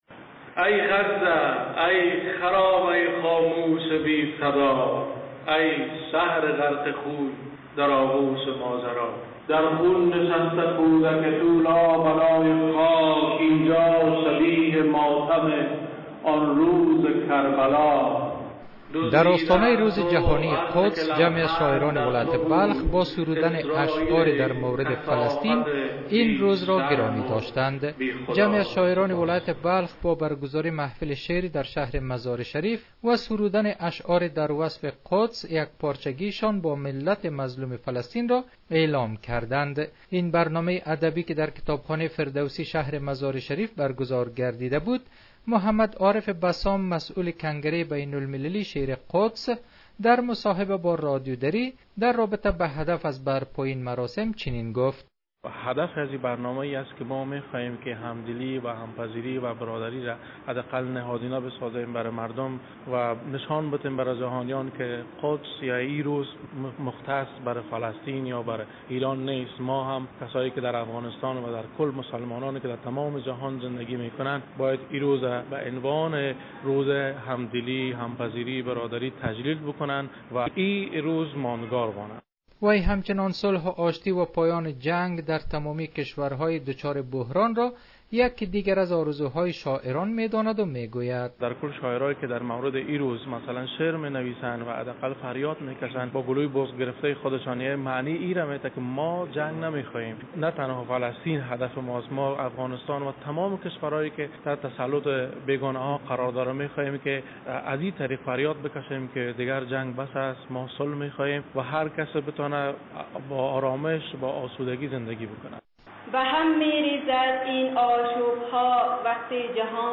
برگزاری محفل شعرخوانی به مناسبت روز قدس در مزار شریف